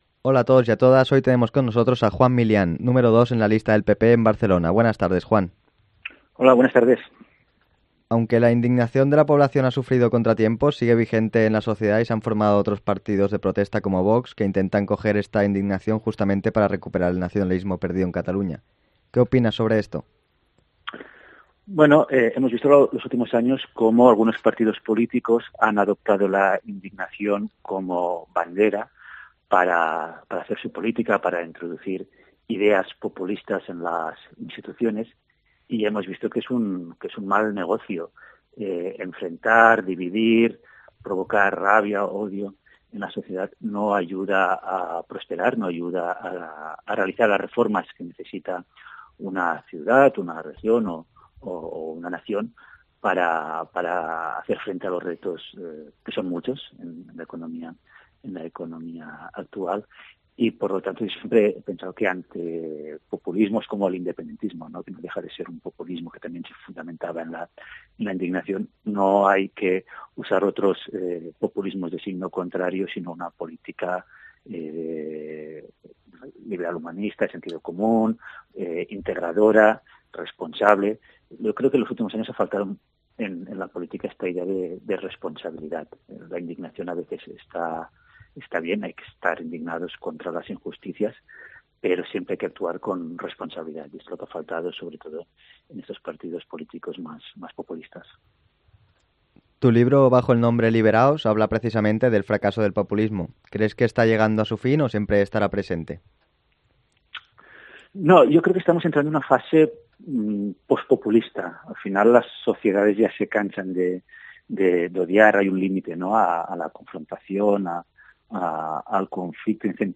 Entrevista a Juan Milián, número dos de la lista del PP en Barcelona